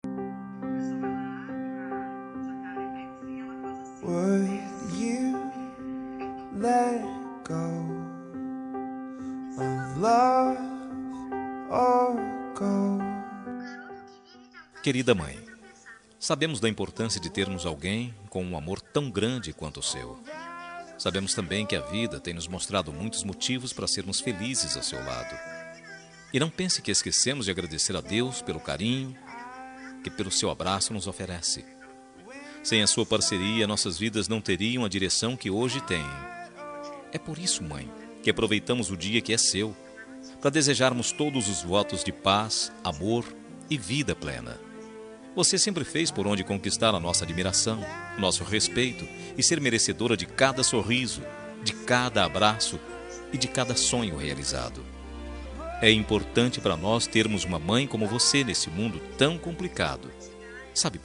Dia das Mães – Para minha Mãe – Voz Masculina – Plural – Cód: 6526